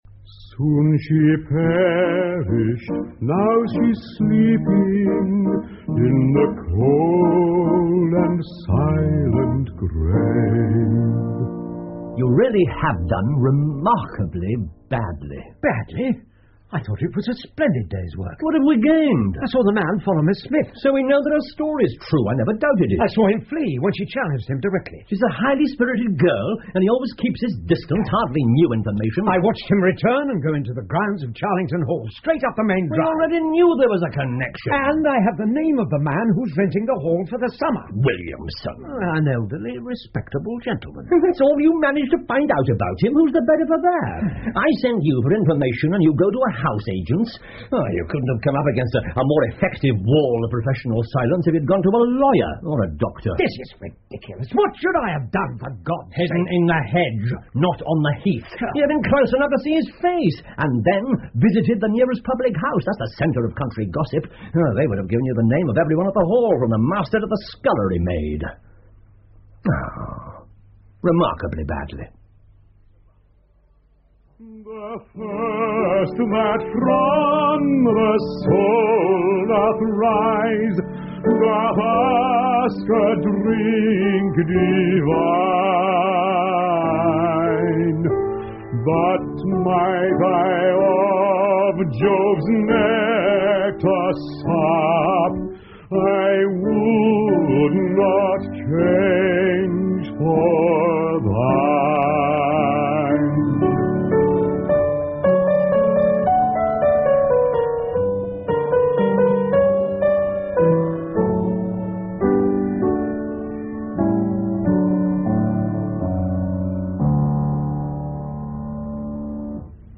福尔摩斯广播剧 The Solitary Cyclist 5 听力文件下载—在线英语听力室